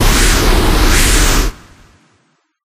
Wind11.ogg